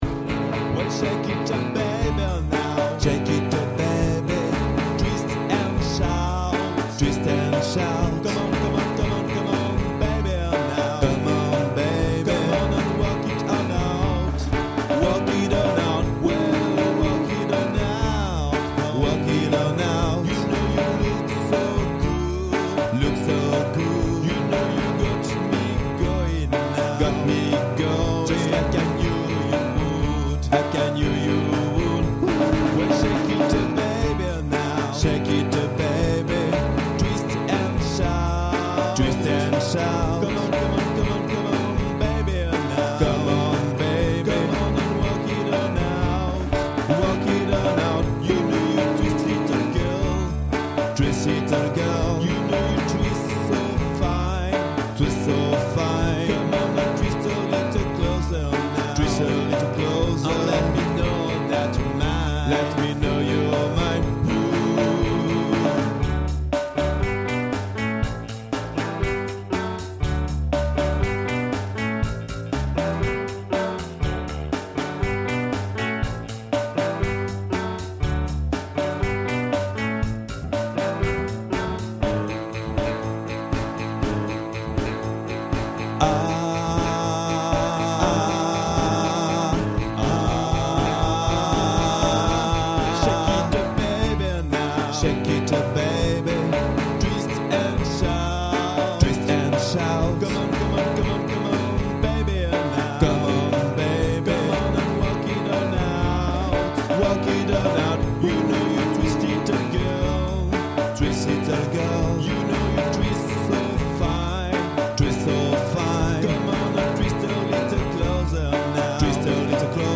Vous trouverez ici les voix enregistrées à télécharger au format MP3 pour vous aider à apprendre votre voix avec les paroles.
Soprano 1